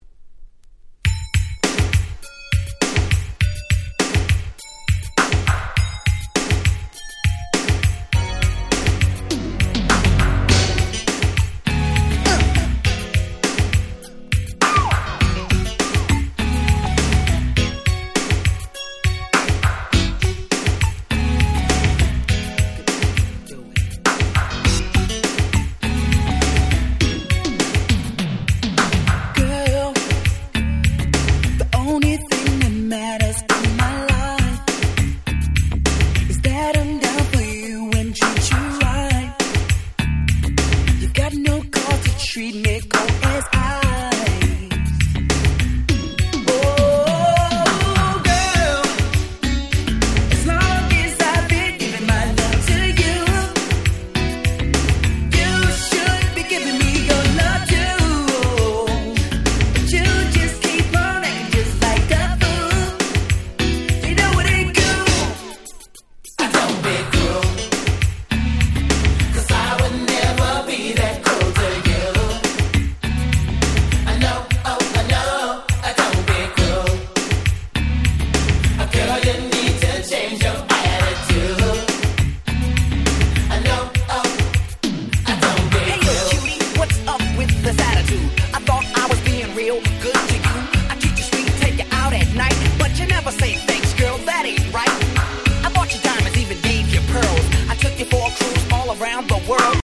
※一部試聴ファイルは別の盤から録音してございます。
88' Super Hit New Jack Swing !!
80's NJS ニュージャックスウィング ハネ系　R&B